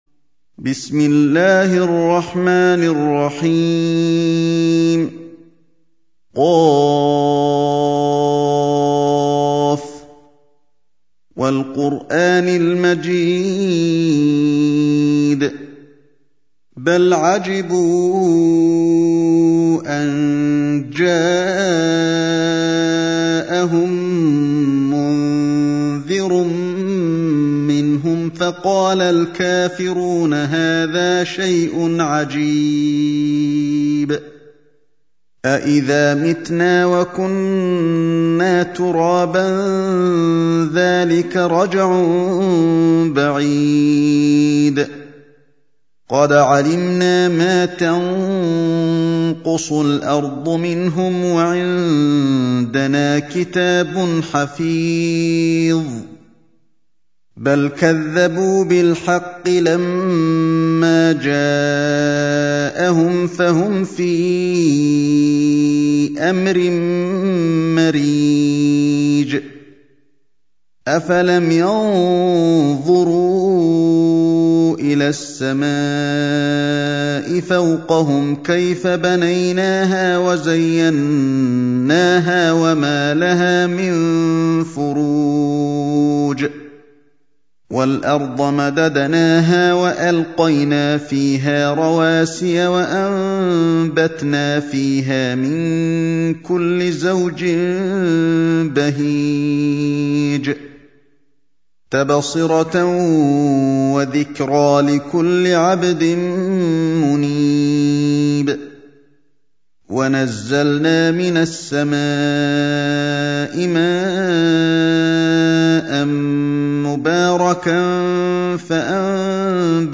50. Surah Q�f. سورة ق Audio Quran Tarteel Recitation
Surah Repeating تكرار السورة Download Surah حمّل السورة Reciting Murattalah Audio for 50. Surah Q�f. سورة ق N.B *Surah Includes Al-Basmalah Reciters Sequents تتابع التلاوات Reciters Repeats تكرار التلاوات